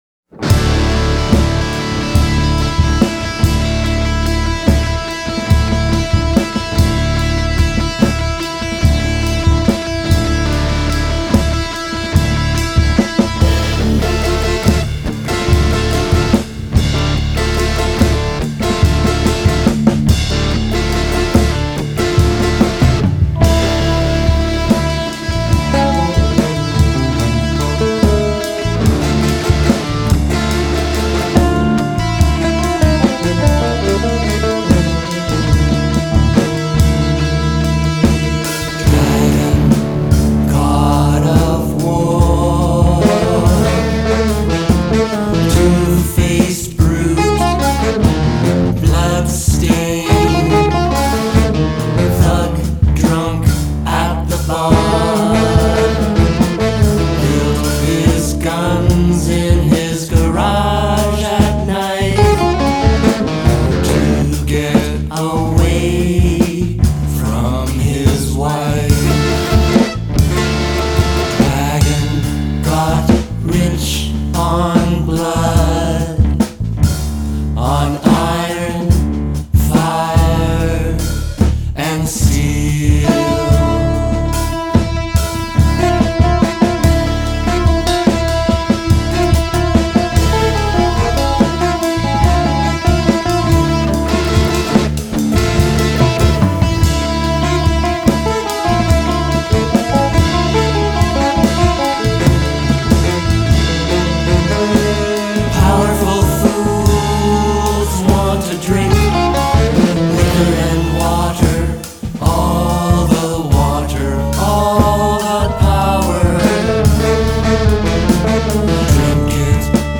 Recorded in Winnipeg and Toronto in Winter/Spring, 2021
bassoon with effects
guitar
vocals/keyboards/2nd guitar/field recordings
bass/vocals
drums/percussion
guest vocalist